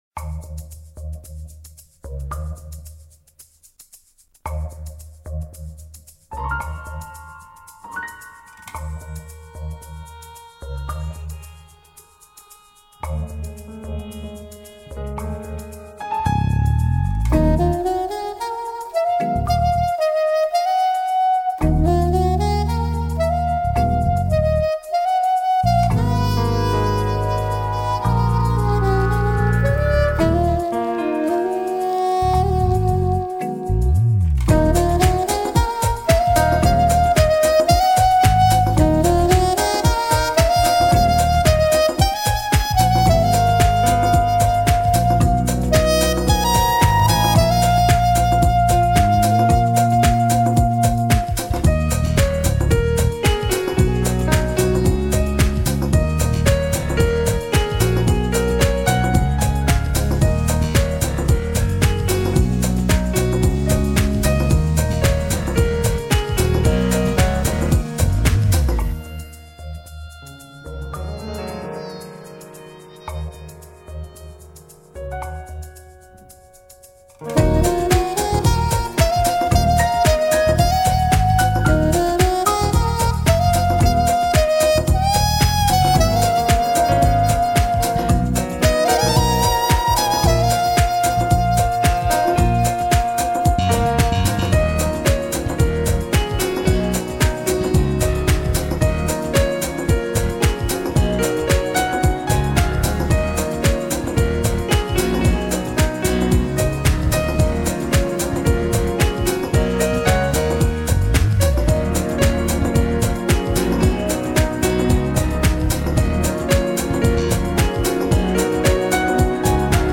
пианистка
исполняющая музыку в стиле нью эйдж и джаз.